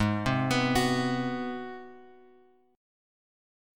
G# 7th Sharp 9th